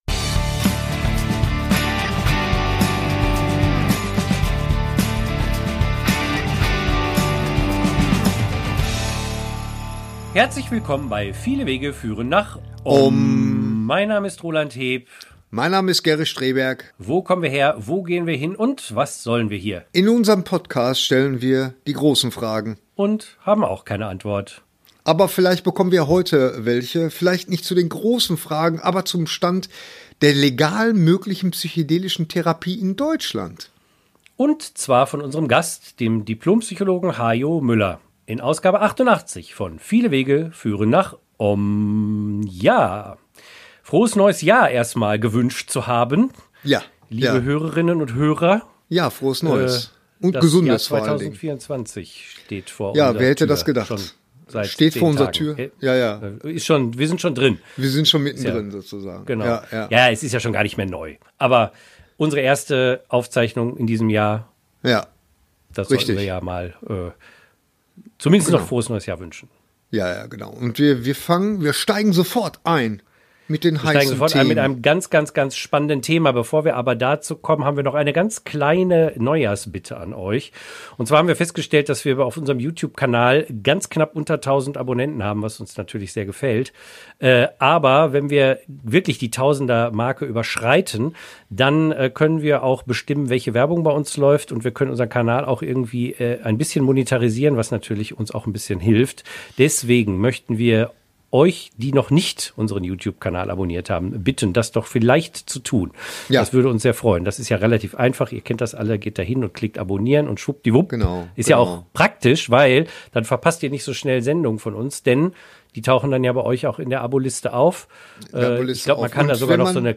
Gesprächs